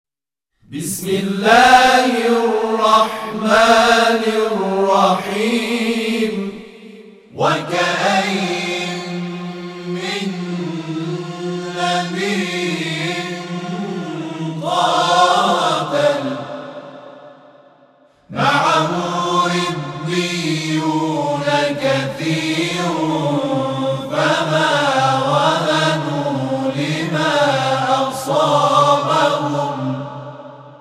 د محمد رسول الله(ص) مداحي ډلې له خوا د آل عمران سورئې د۱۴۶ آیت ډله ایز تلاوت
ایکنا -  د محمد رسول الله(ص) مداحي همخوانئ ډلې غړو  د آل عمران سورې د ۱۴۶آیت په ګډه تلاوت وړاندې کړ.